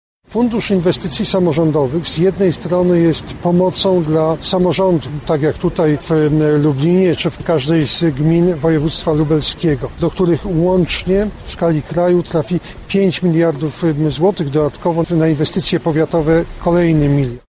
W takich słowach wyraził się Jarosław Gowin podczas swojej wizyty w Lublinie.
Fundusz Inwestycji Samorządowych jest jedną z najbardziej udanych reform wprowadzonych po 89 roku – mówi przewodniczący klubu parlamentarnego Zjednoczona Prawica Jarosław Gowin.